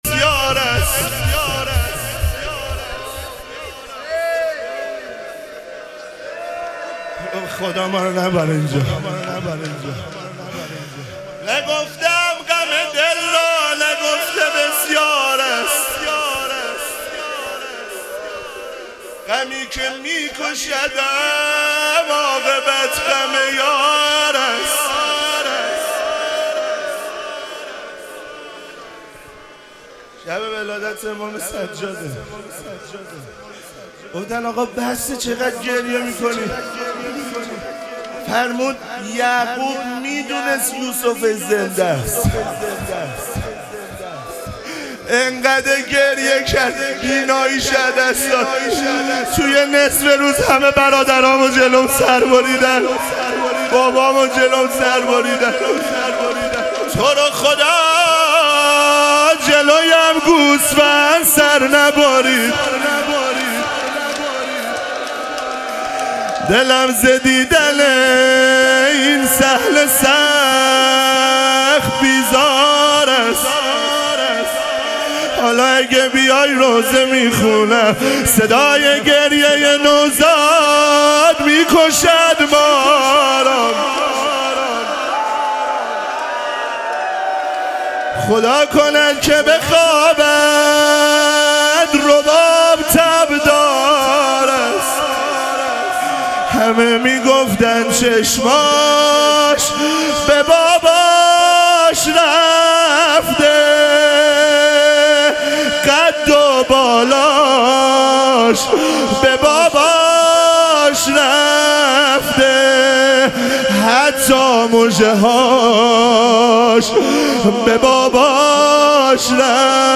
ظهور وجود مقدس امام سجاد علیه السلام - روضه